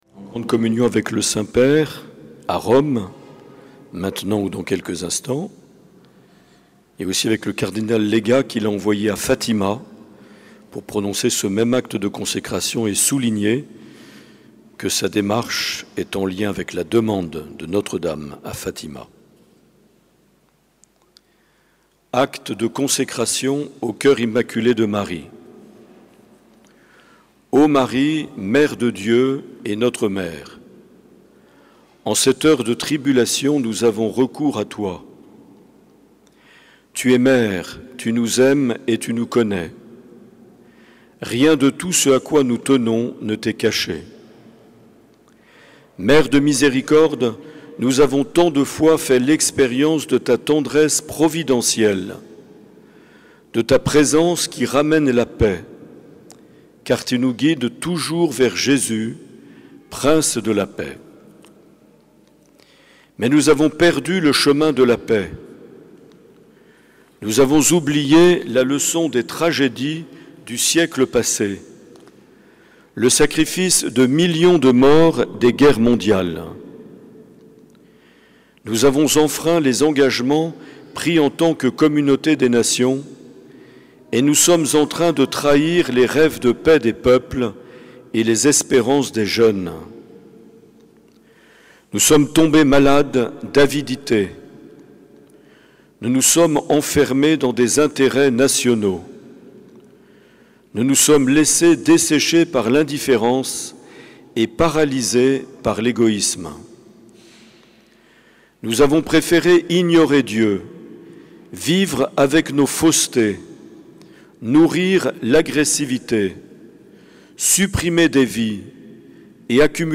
En communion avec le pape François, acte public de consécration de Mgr Marc Aillet le 25 mars 2022 en la cathédrale sainte Marie de Bayonne.